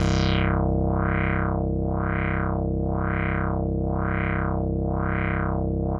Index of /90_sSampleCDs/Trance_Explosion_Vol1/Instrument Multi-samples/LFO Synth
C2_lfo_synth.wav